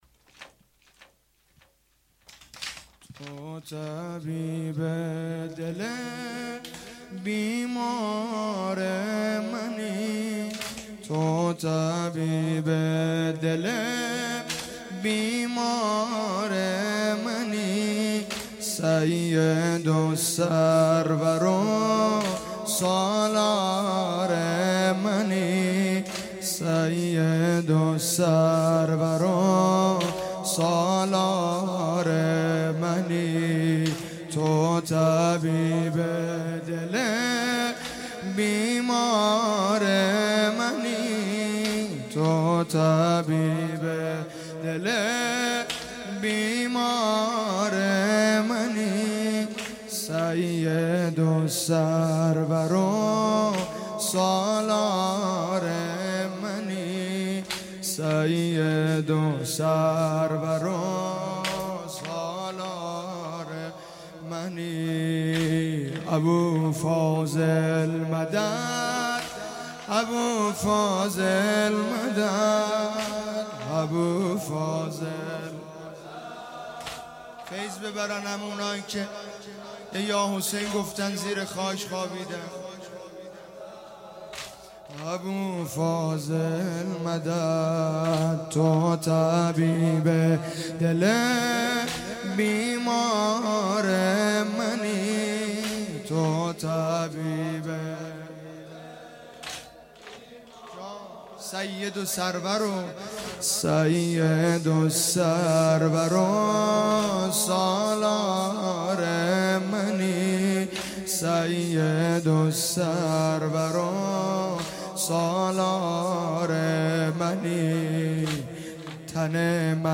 اقامه عزای روضه حضرت صدیقه شهیده علیها السلام _ شب سوم